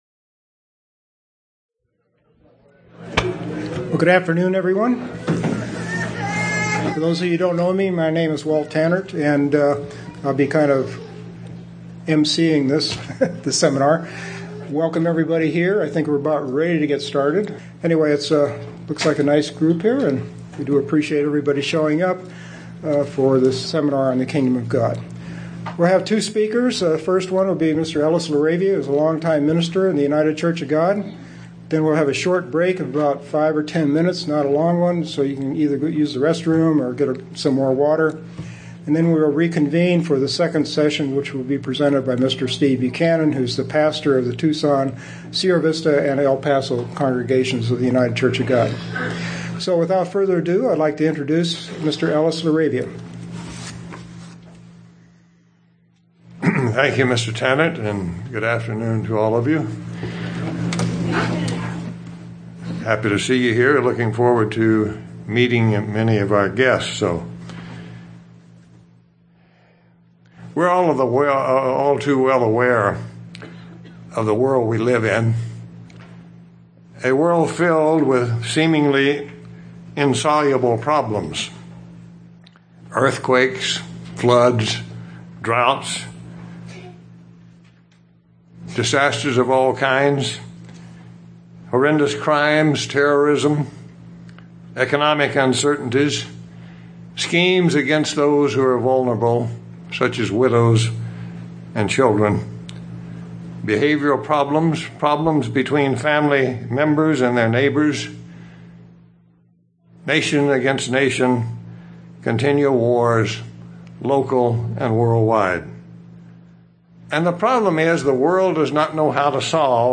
This first-in-a-series Kingdom of God Bible seminar presents messages the world needs to hear. First, just what is the Kingdom of God?
Given in Tucson, AZ